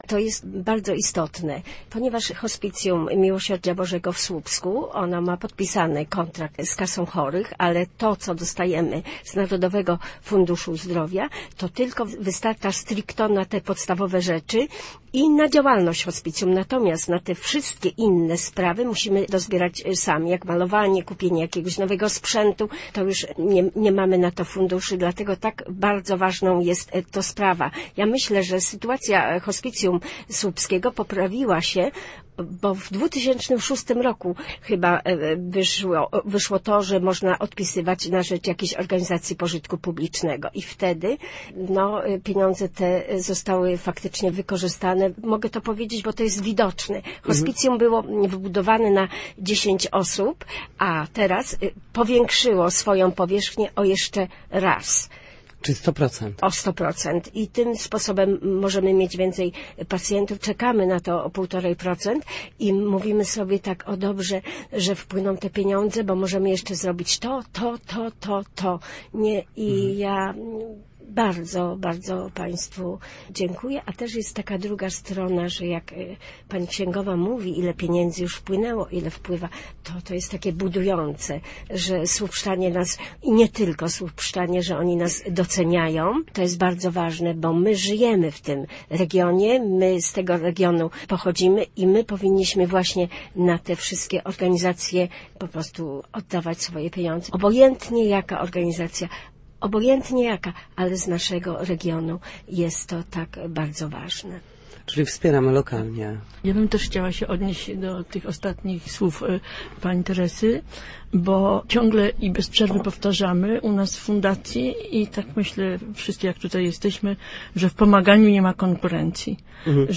Posłuchaj materiału reporterki: